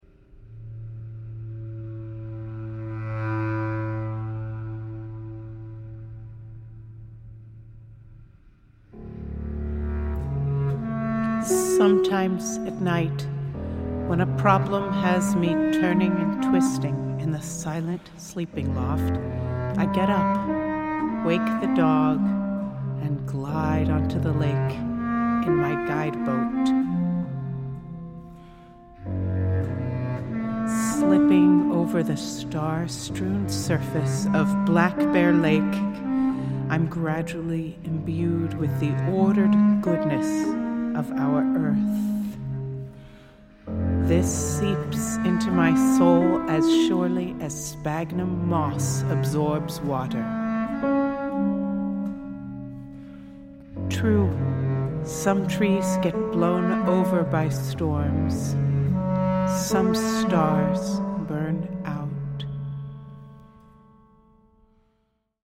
piano
bass clarinet